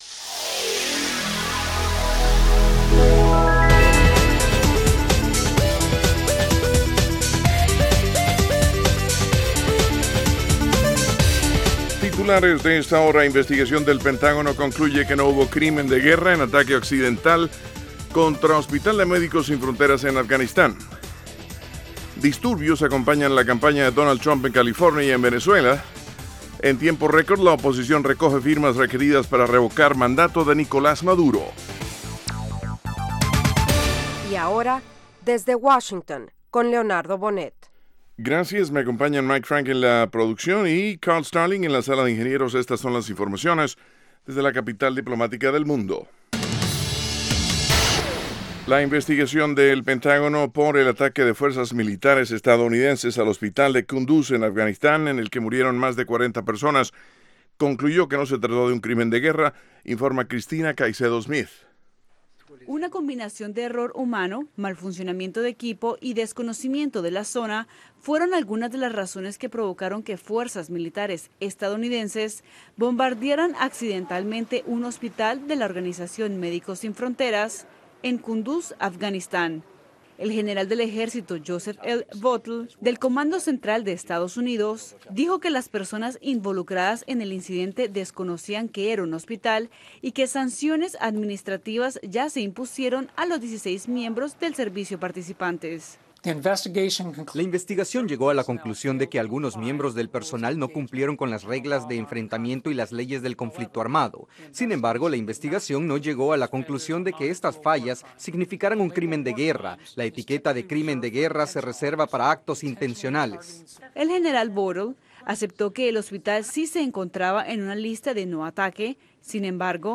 Diez minutos con las noticias más relevantes del día, ocurridas en Estados Unidos y el resto del mundo.